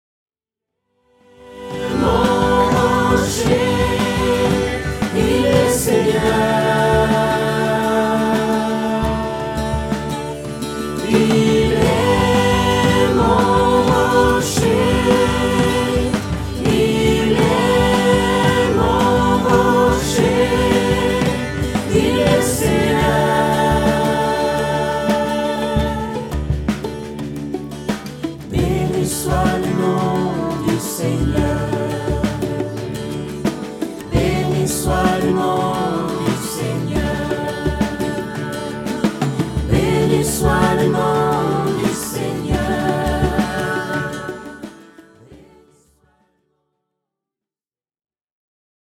ensemble vocal
Format :MP3 256Kbps Stéréo